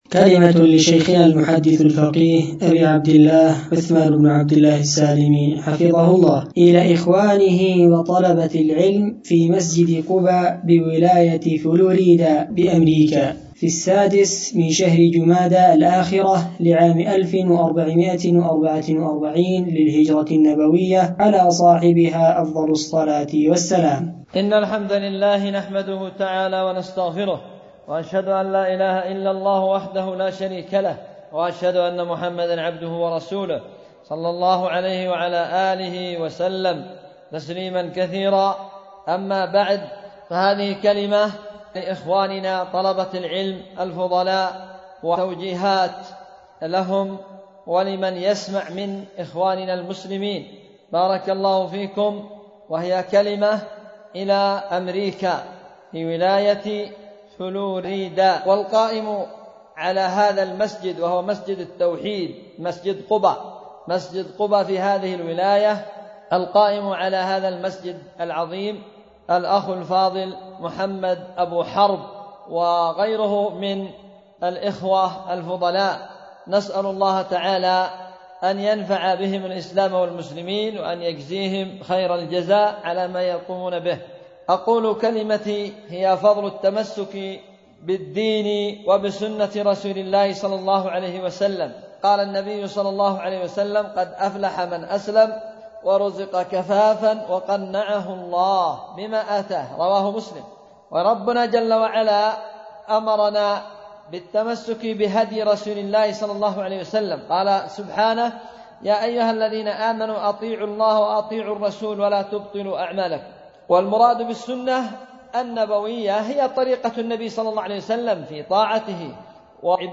كلمة: إلى مسجد قباء بولاية فلوريدا بأمريكا